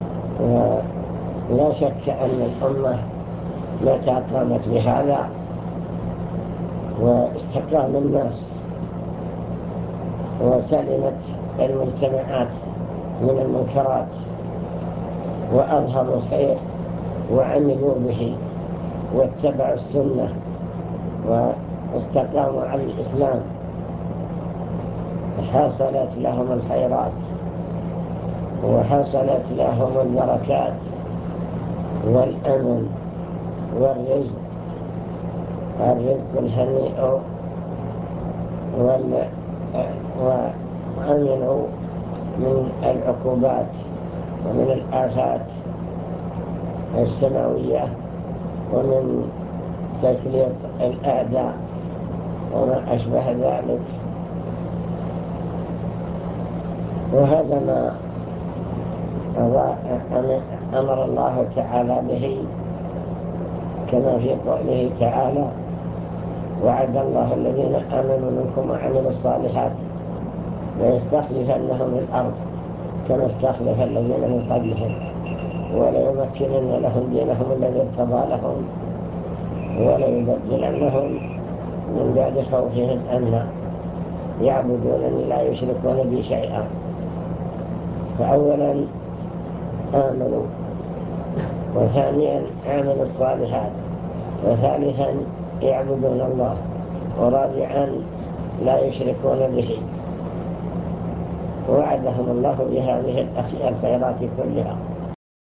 المكتبة الصوتية  تسجيلات - لقاءات  كلمة في الهيئة